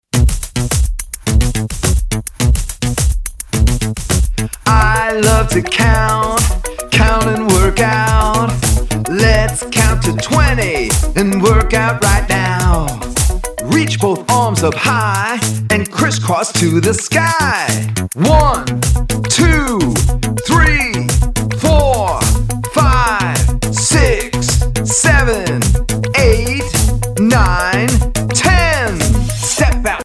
A fun counting song from fan favorite
Early Math and Countingmath Songs action songs Preschool